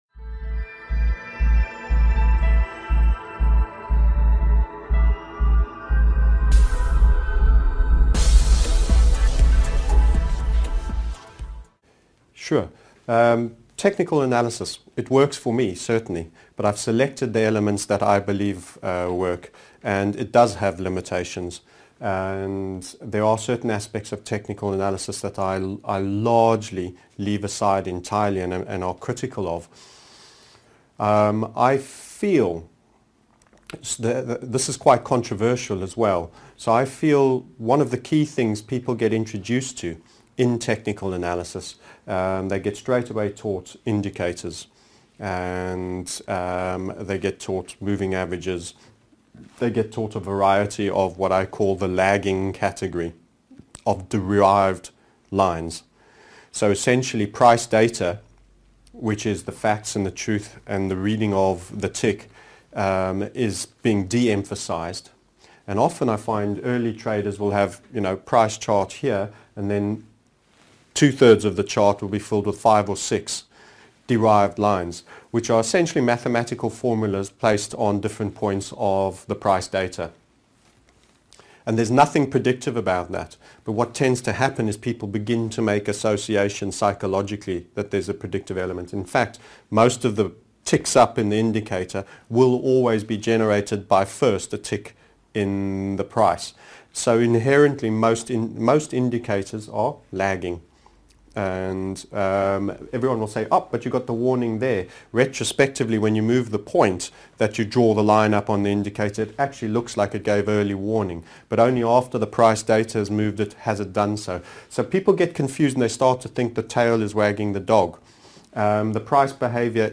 Technical Analysis Why It Works And Limitations - TMS Interviewed Series - 4 0f 32